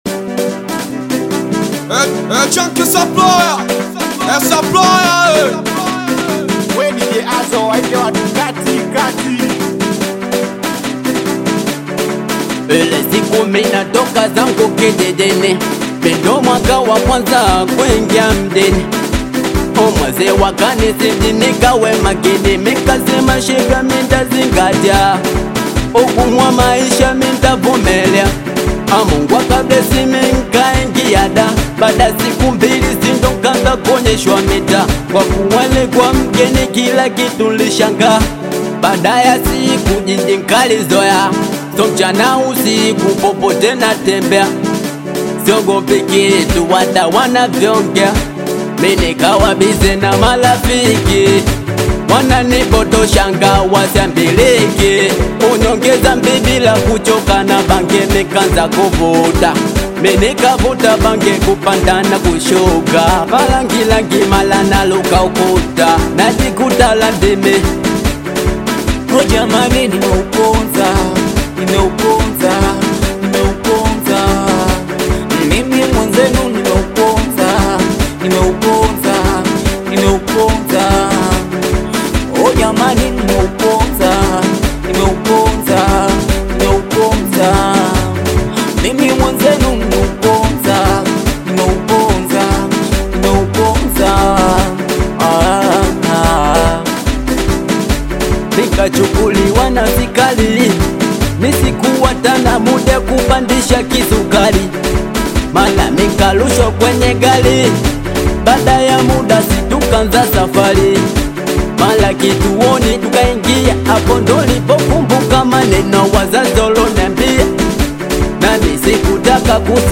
With its intoxicating beat and soulful melodies
traditional African rhythms with a modern, emotive touch
powerful vocals soar